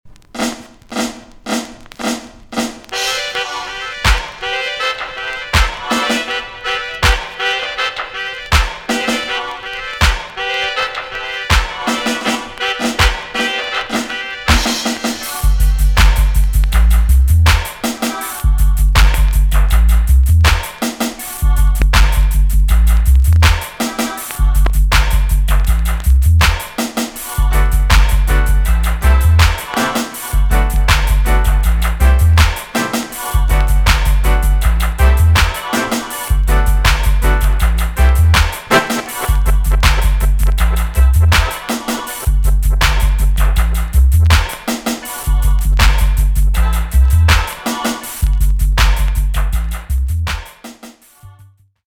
TOP >80'S 90'S DANCEHALL
B.SIDE Version
EX-~VG+ 少し軽いチリノイズが入りますが良好です。